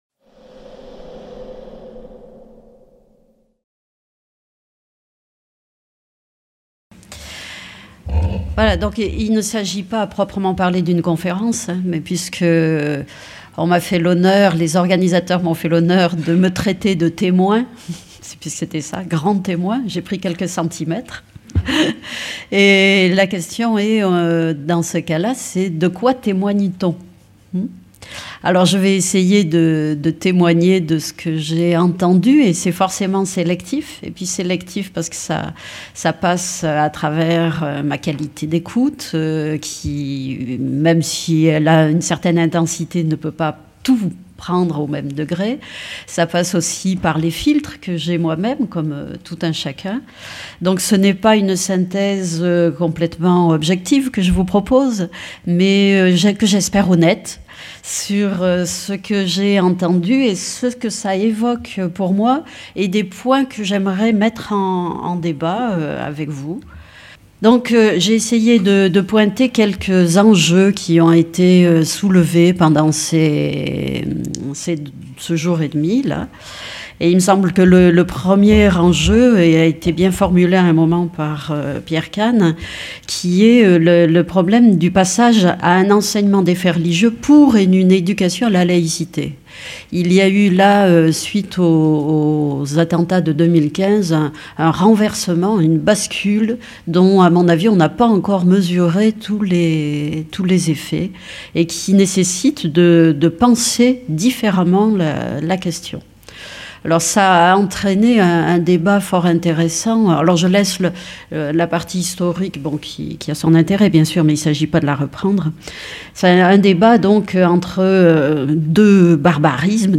08 – Conférence de clôture | Canal U